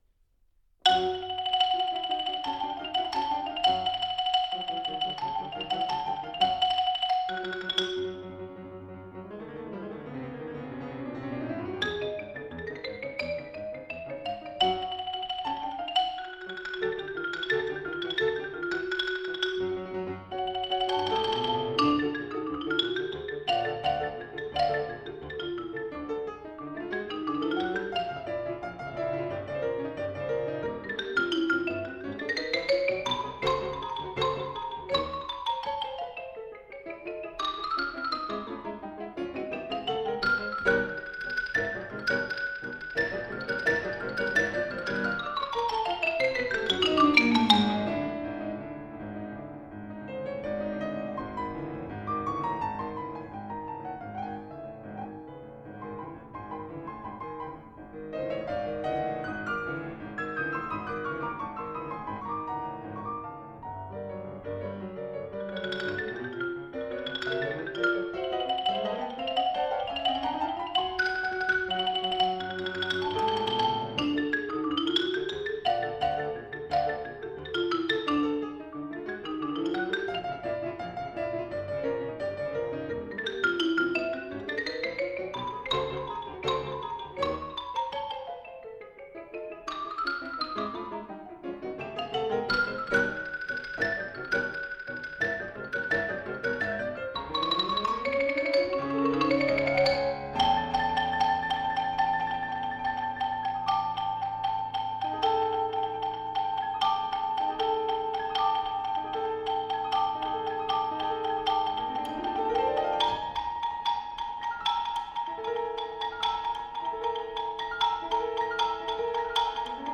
マリンバ、打楽器奏者。
ピアノ伴奏